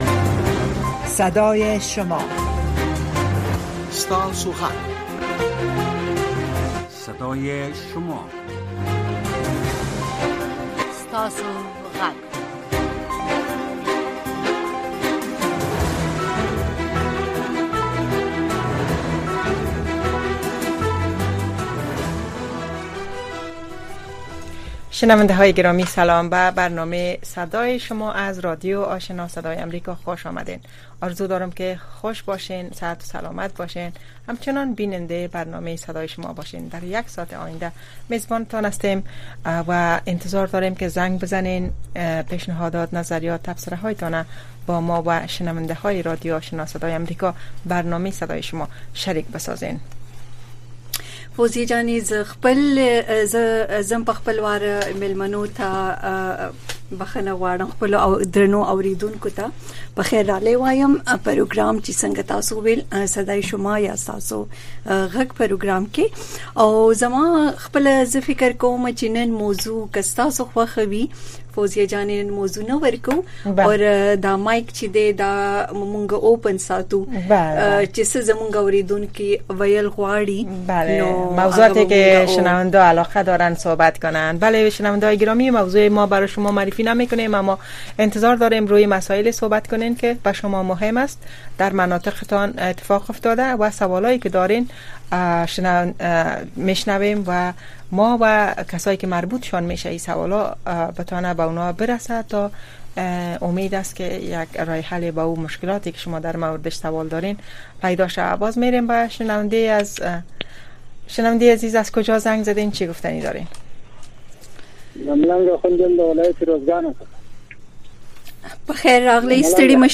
در برنامۀ صدای شما، شنوندگان رادیو آشنا صدای امریکا مستقیماً با ما به تماس شده و نگرانی‌ها، دیدگاه‌ها، انتقادات و شکایات شان را با گردانندگان و شنوندگان این برنامه در میان می‌گذارند. این برنامه به گونۀ زنده از ساعت ۹:۳۰ تا ۱۰:۳۰ شب به وقت افغانستان نشر می‌شود.